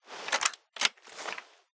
Wpn_riflelaser_jam.ogg